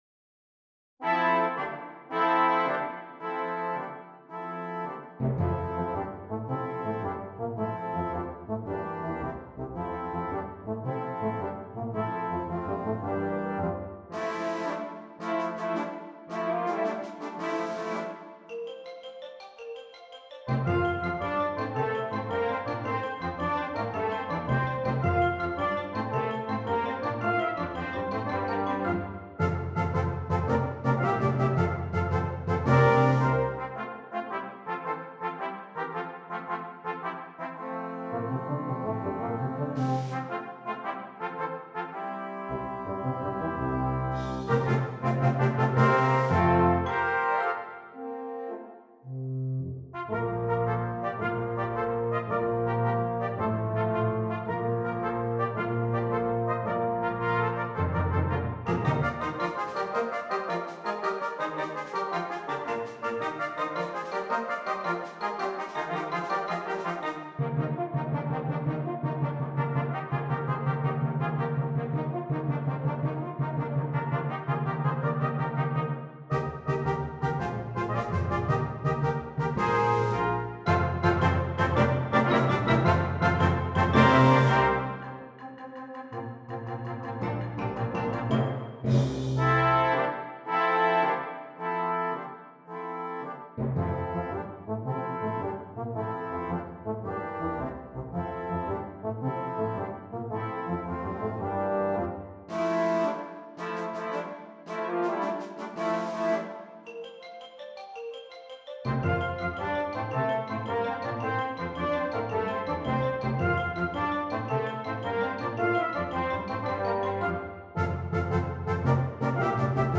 Brass Choir (4.2.2.1.1.perc)
Traditional